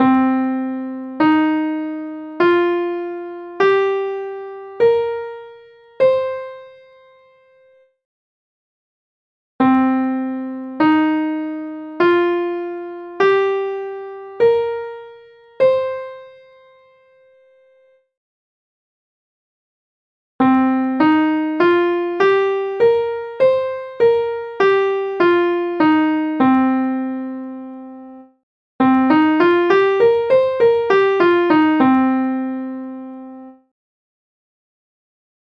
הנה סולם רגיל (פנטטוני מינורי למי שרוצה לדעת – סולם עם נופך בלוזי לכשעצמו) מנוגן פעמים לאט ואז מהר:
scale-pent.mp3